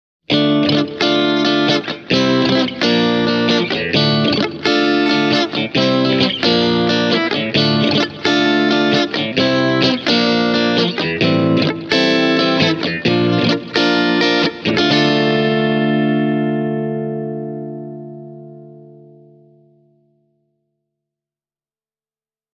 The Bloom-circuit in the Bogner Harlow sounds deliciously chewy and reacts very musically.
There’s a nice attack click, when played with a Stratocaster:
bogner-harlow-e28093-stratocaster.mp3